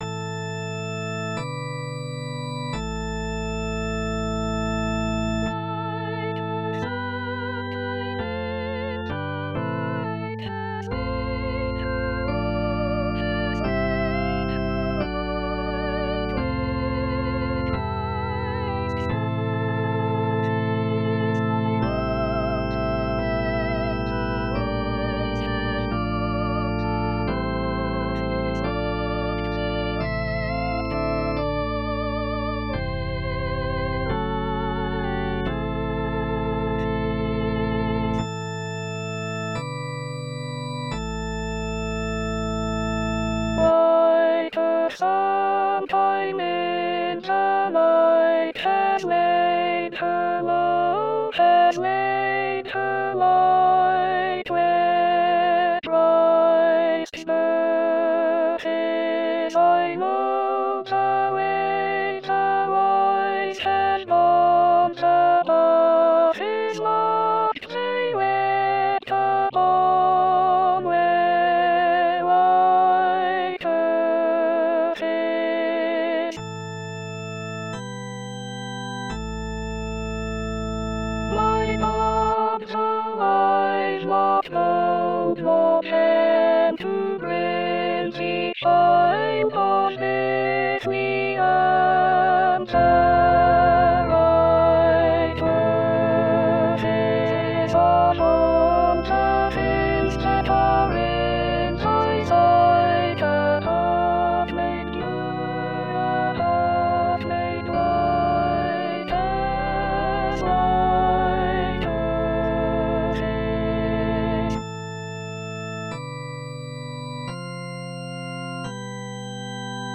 Practice audio files: • Soprano   • Alto
Number of voices: 4vv   Voicing: S solo & SATB
Genre: SacredCarol
Instruments: Organ